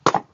splitlog.ogg